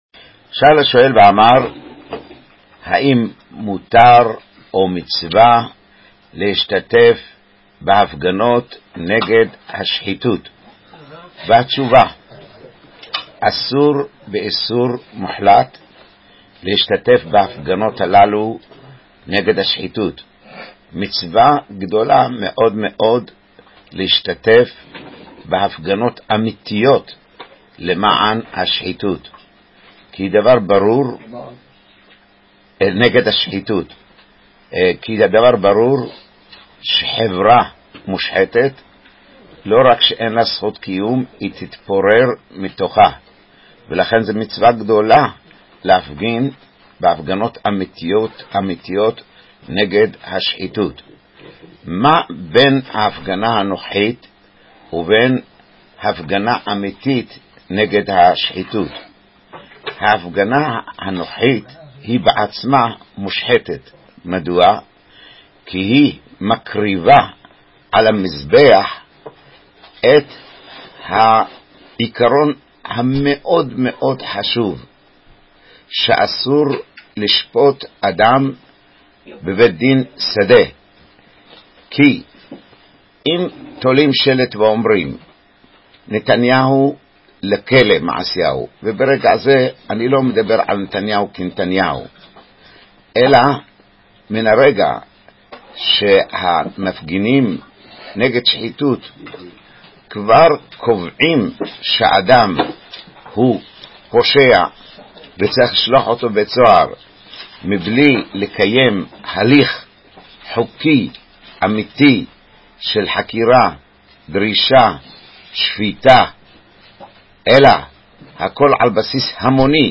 בשיעורו השבועי מתמודד הרב עם השאלה: "האם מותר או מצווה להשתתף בהפגנות נגד השחיתות?"